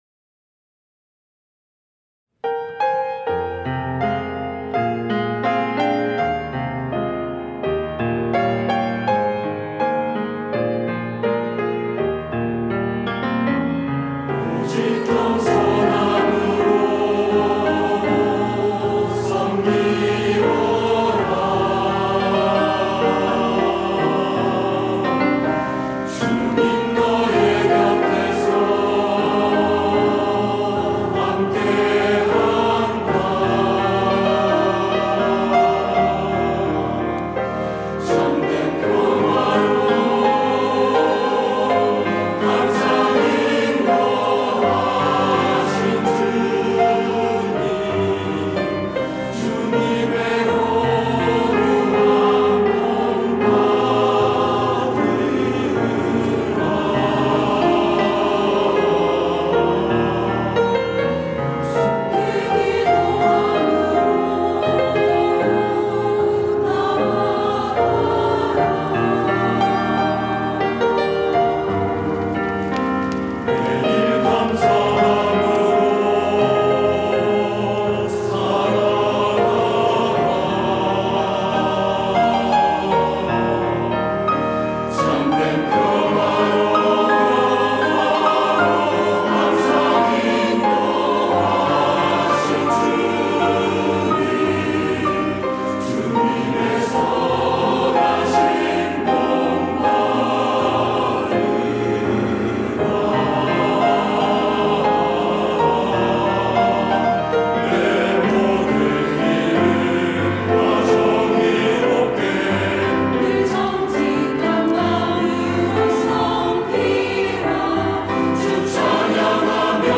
할렐루야(주일2부) - 오직 겸손함으로
찬양대